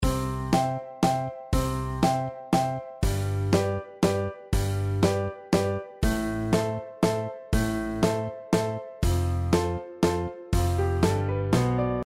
Надеюсь, что с 2/4 все понятно, потому что пришло время перейти к размеру 3/4 - три доли, по длительности - четвертные.
В басовом ключе играют только четвертные ноты и четвертные паузы.
Valse.mp3